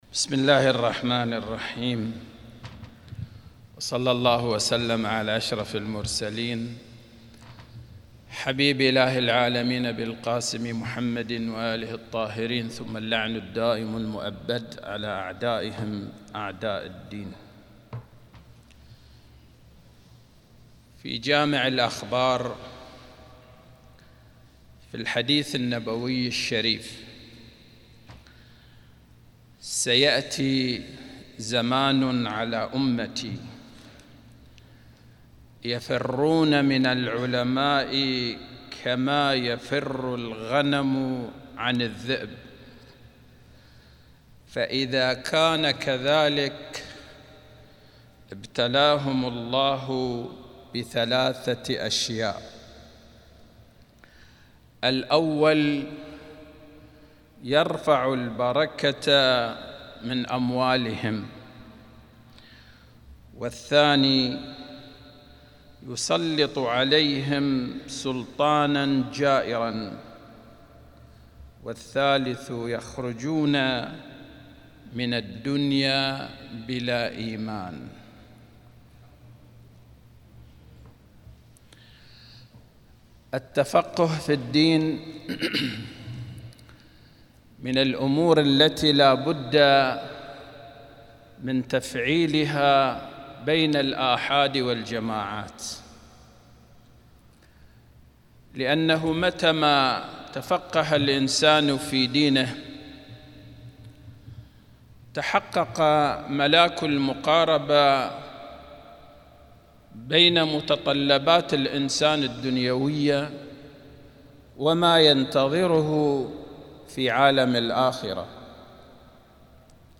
القسم : محاضرات يوم الجمعه بجامع الإمام الحسين عليه السلام